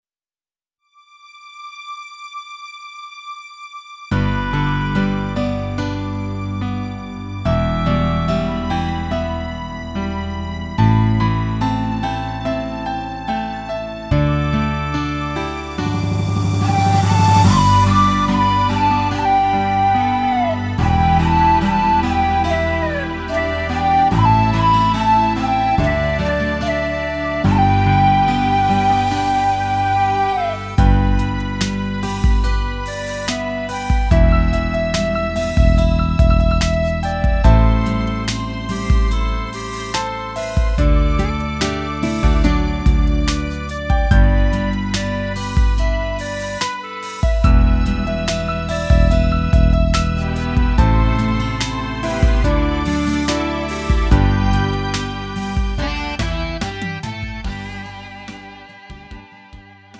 음정 -1키 3:39
장르 구분 Lite MR